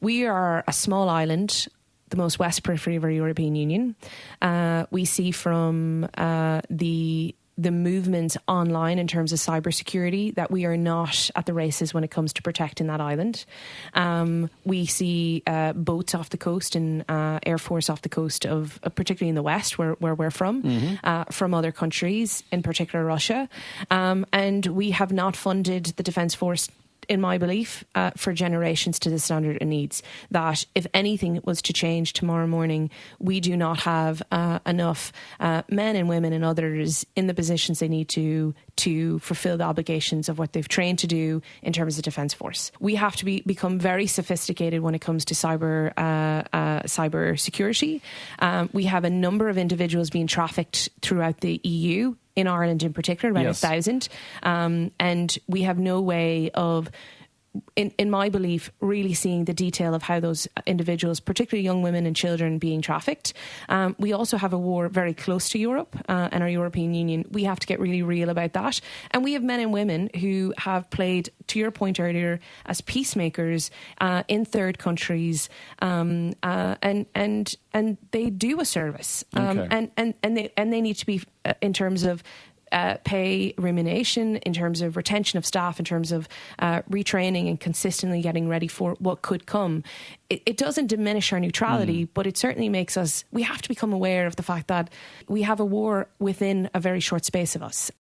However, speaking on today’s Nine til Noon Show live at the European Parliament, she says there are many elements that needs to be looked at: